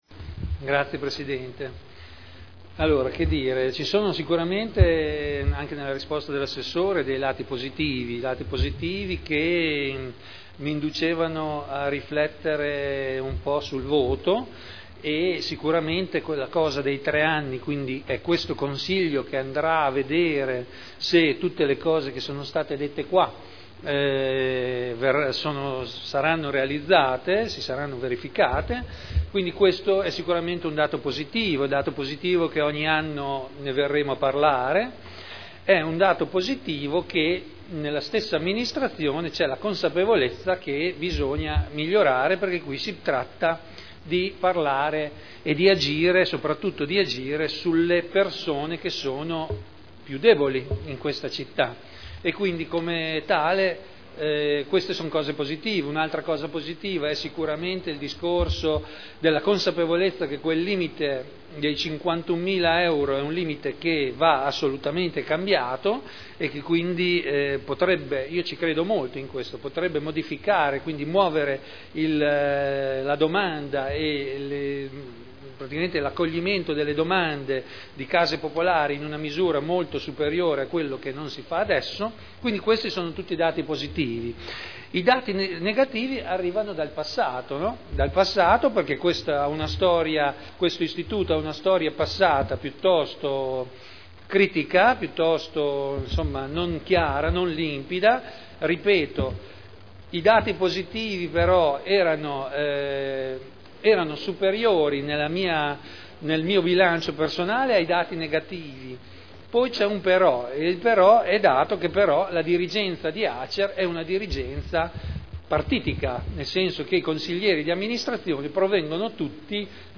Seduta del 20/12/2010. Dichiarazione di voto su delibera: Affidamento in concessione all’Azienda Casa Emilia Romagna del servizio di gestione del patrimonio di Edilizia Residenziale pubblica di proprietà del Comune – Approvazione Accordo Quadro provinciale e Contratto di Servizio del Comune di Modena (Commissione consiliare del 7 dicembre 2010)